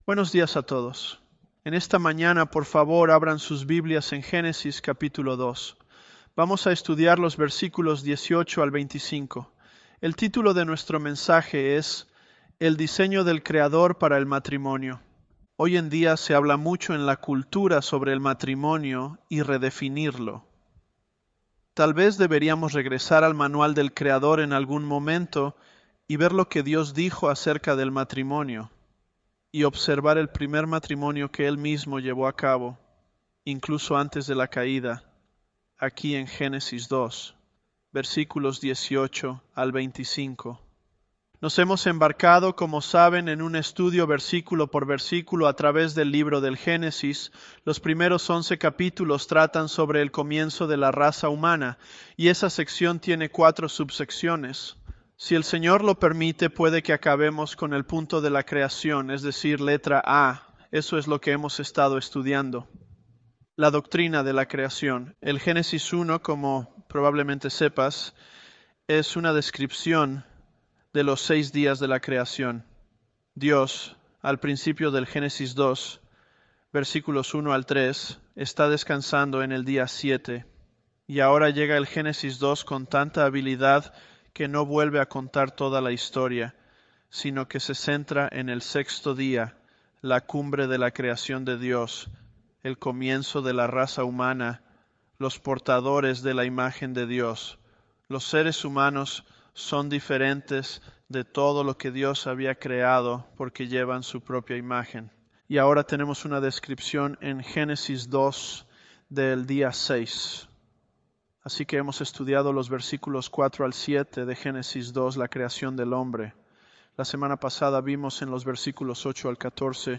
Sermons
ElevenLabs_Genesis-Spanish010.mp3